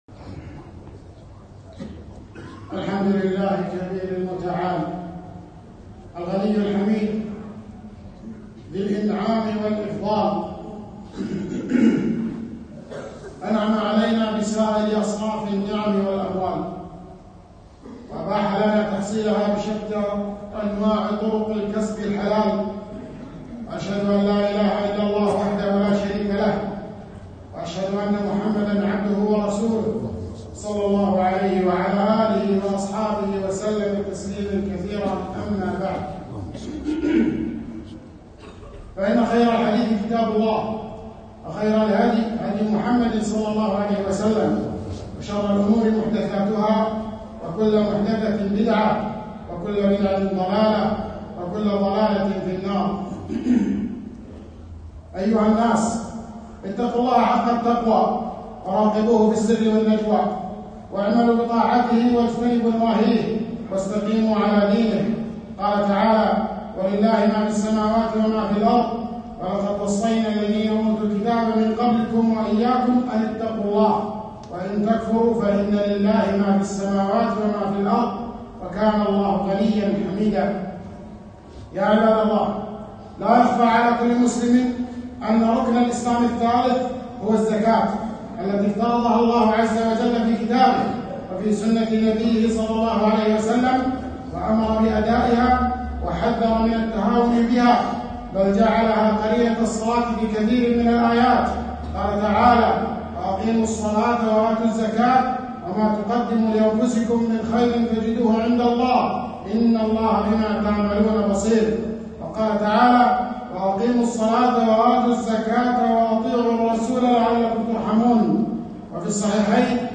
خطبة - الزكاة ركن الإسلام الثالث